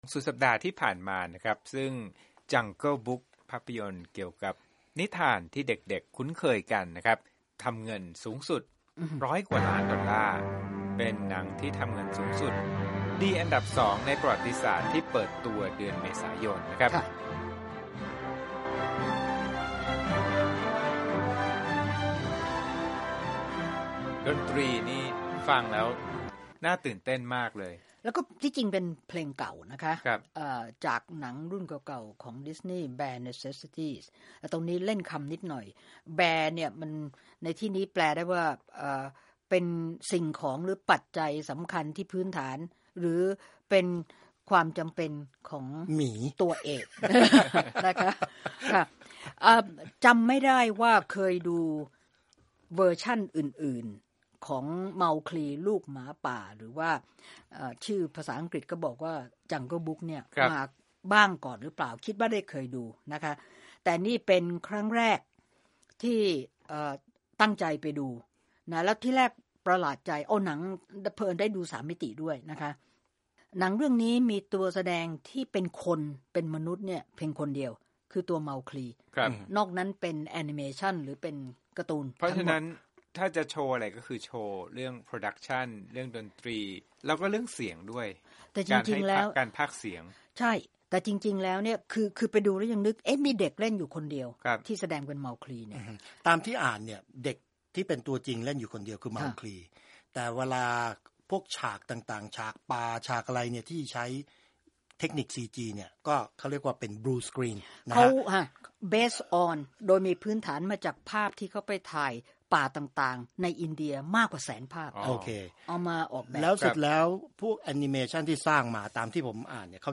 วิจารณ์ภาพยนตร์
Jungle Book Movie Review